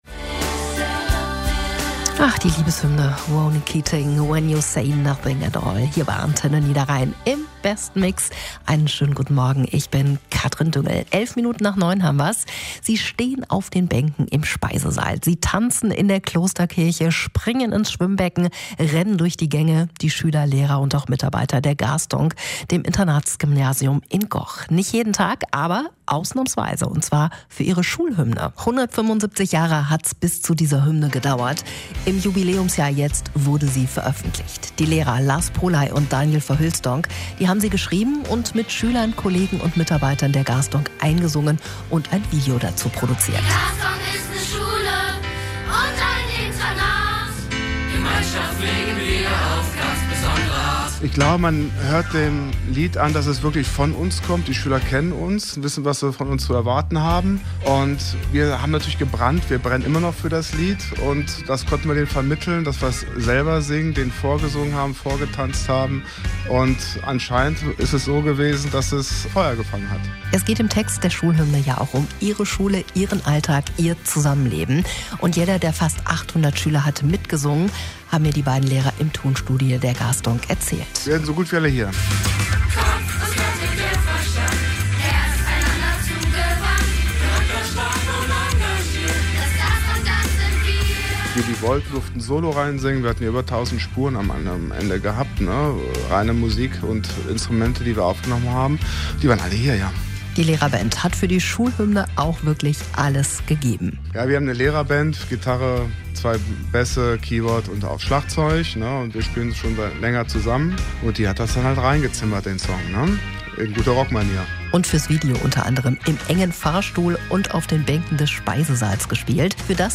Gaesdoncker_Schulhymne-1.mp3